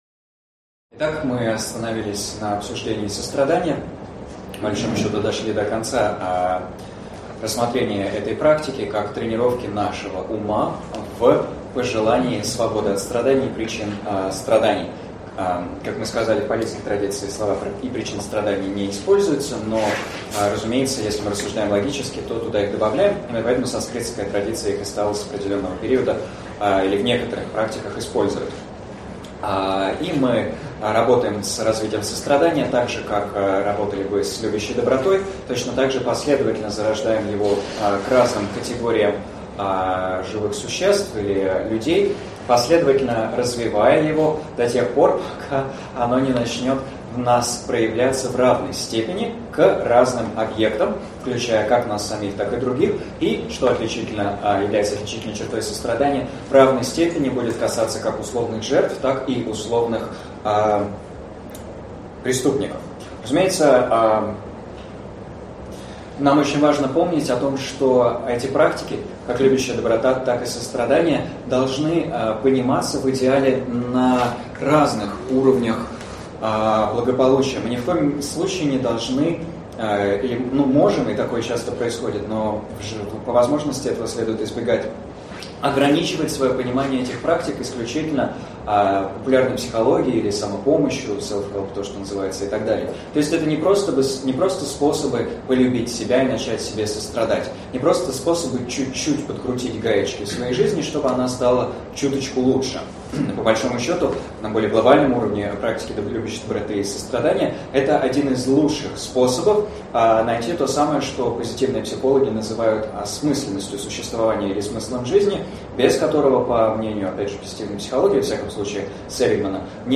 Аудиокнига Осознанная доброжелательность и четыре безмерных. Часть 5 | Библиотека аудиокниг